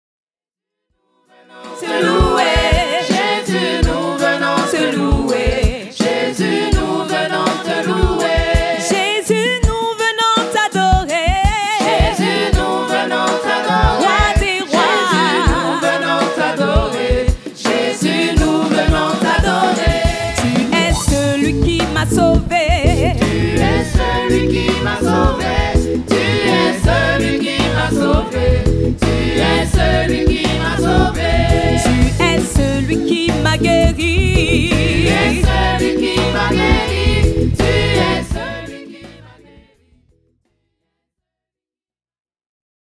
Enregistrement public réalisé à Genève en 2006